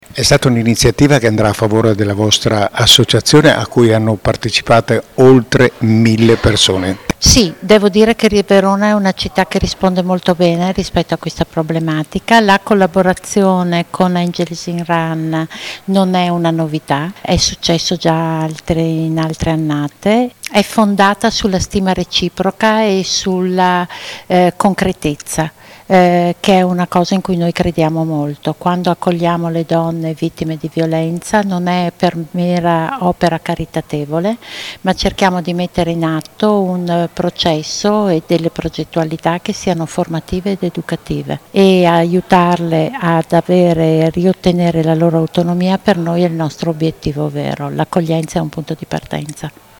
Le dichiarazioni raccolte dal nostro corrispondente